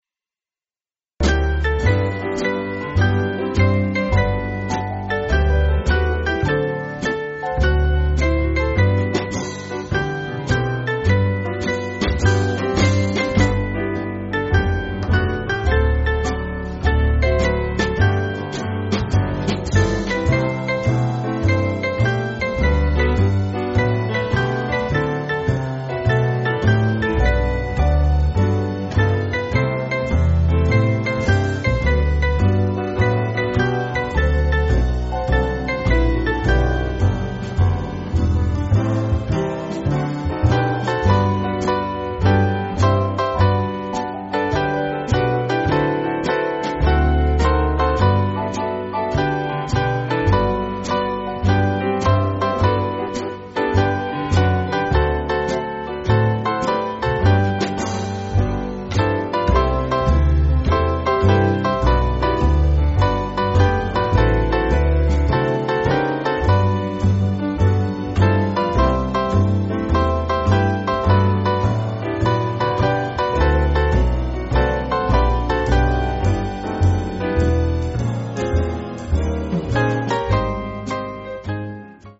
Swing Band
(CM)   3/G-Ab